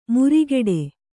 ♪ murigeḍe